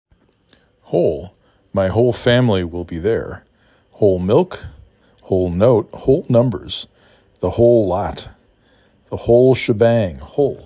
h O l